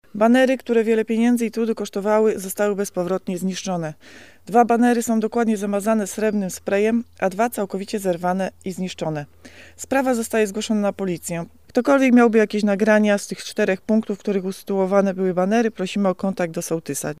Dziś są zbulwersowani – mówi sołtyska wsi Koło Aleksandra Szkodzińska-Zajączkowska.